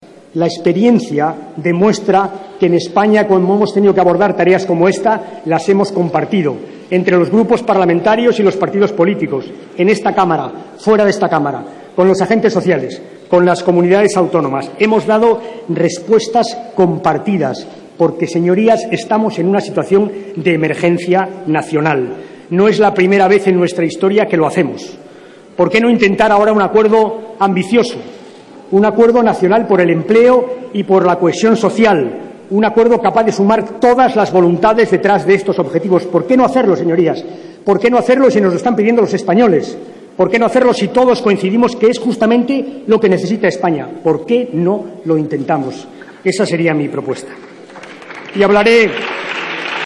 Alfredo P. Rubalcaba. Debate del Estado de la Nación 20/02/2013